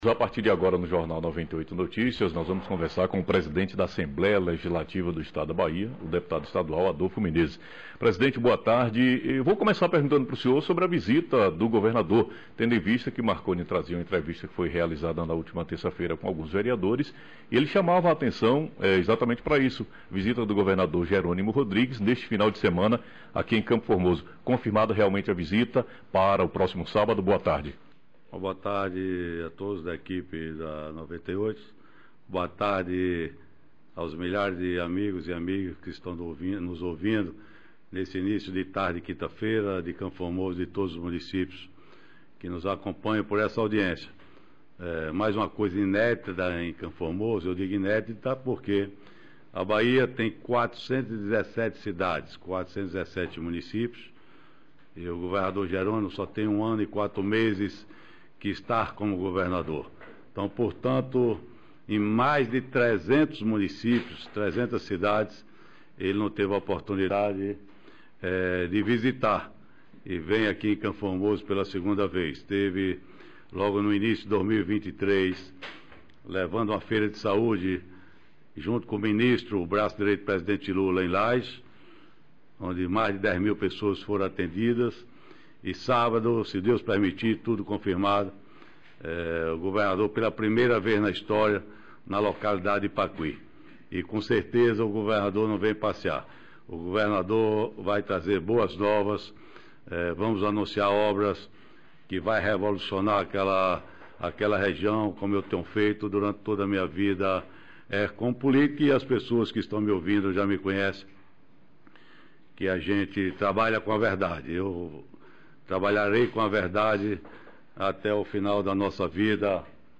Entrevista ao vivo com o presidente da ALBA, Deputado Adolfo Menezes